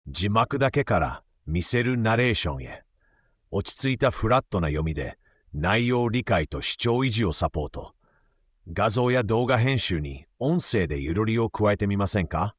WEGEE Voice Studio は、テキストから自然な日本語音声をつくるスタジオ。
落ち着いたフラットな読みで、内容理解と視聴維持をサポート。
VOICE：端正な男性（ナチュラル）
VIBE：親しみ・やさしさ